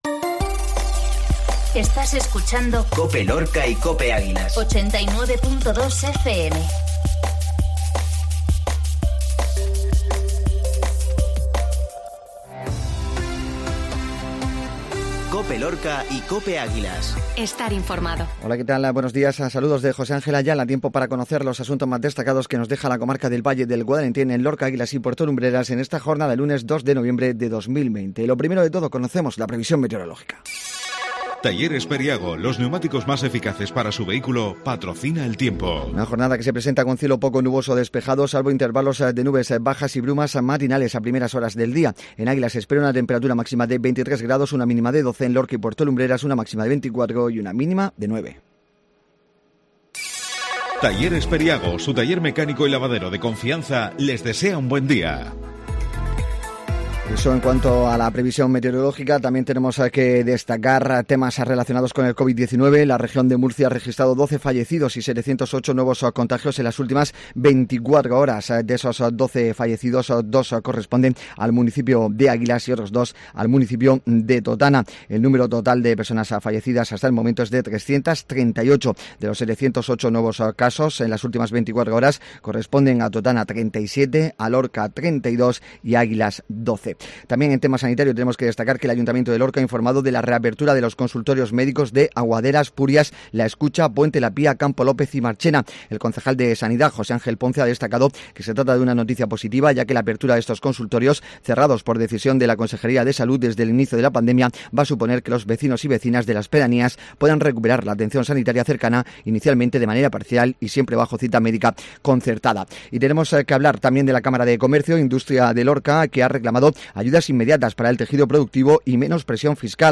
INFORMATIVO MATINAL LUNES 0211